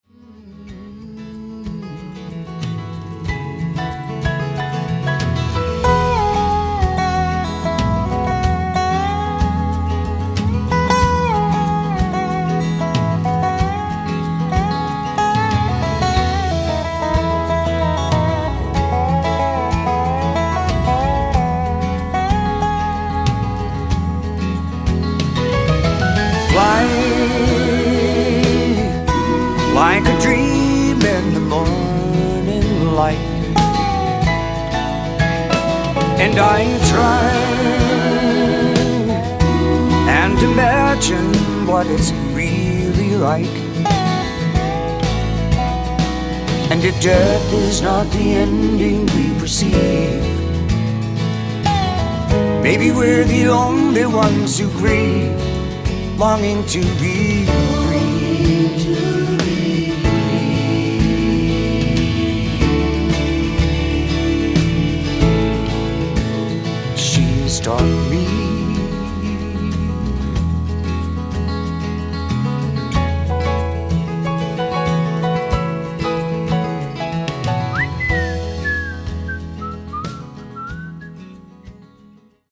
Cello
Bass Guitar
Piano, B3, Backing Vocals
Steel Guitar
6 & 12 String Guitars
Mandolin, Violin, Banjo & Dobro
Drums
Violin